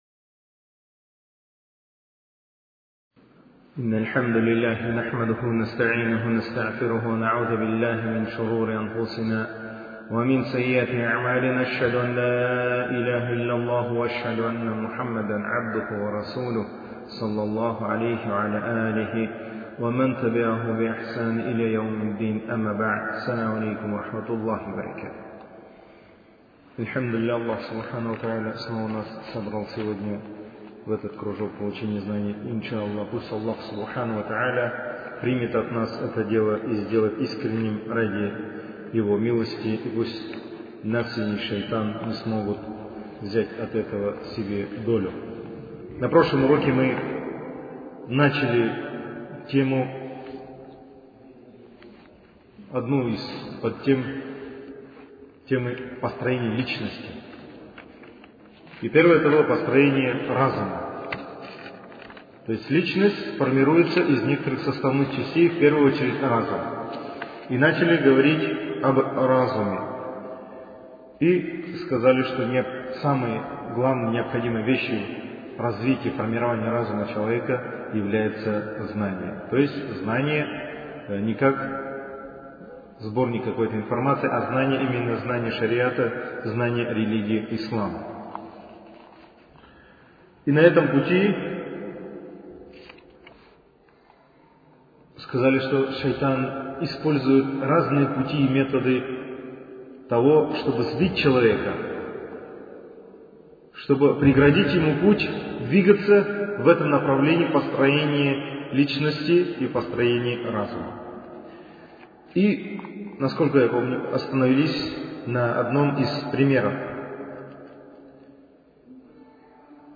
Цикл лекций